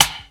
Snare (14).wav